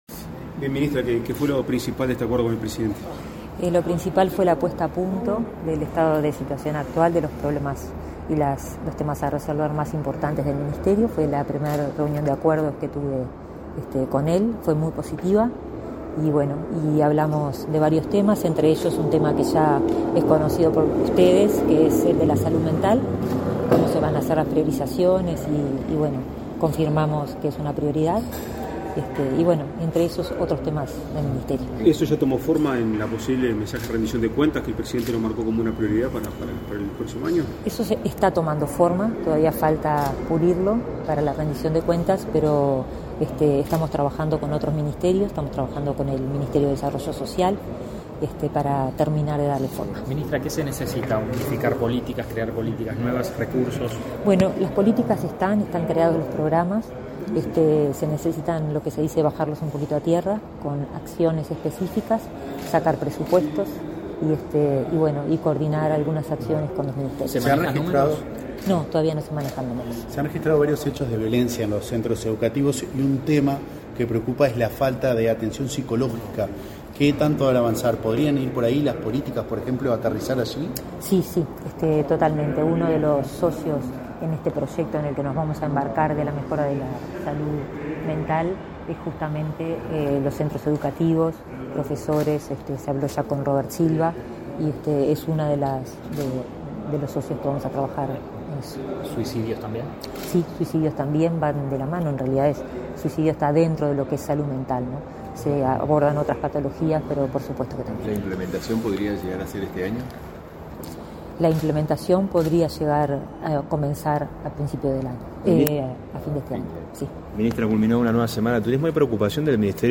Declaraciones a la prensa de la ministra de Salud Pública, Karina Rando